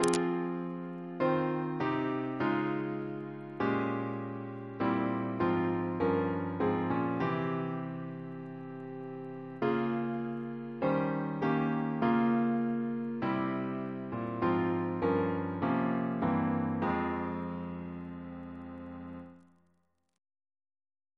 Double chant in F minor Composer: Chris Biemesderfer (b.1958)